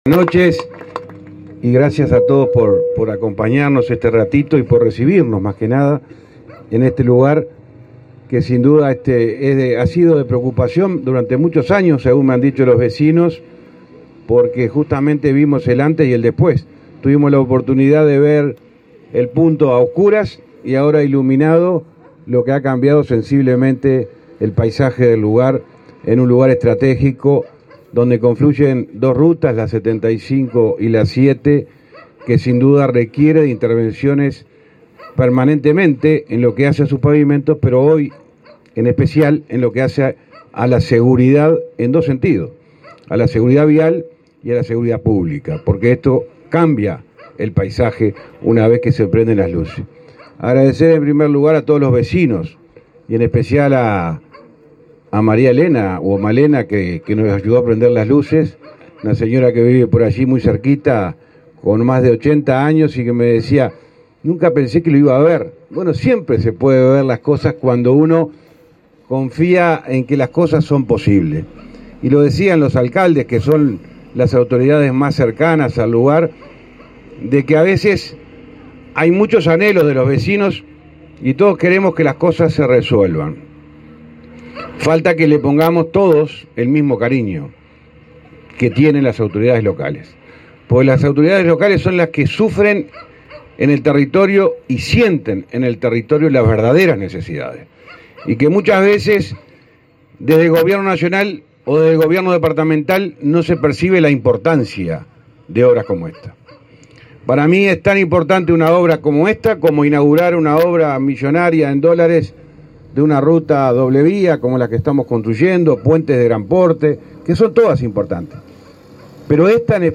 Palabras del ministro de Transporte, José Luis Falero
Palabras del ministro de Transporte, José Luis Falero 27/07/2023 Compartir Facebook X Copiar enlace WhatsApp LinkedIn El Ministerio de Transporte y Obras Públicas inauguró, este 27 de julio, 38 luminarias en las rutas n.° 7 y n.° 75. En el evento participó el titular de la cartera, José Luis Falero.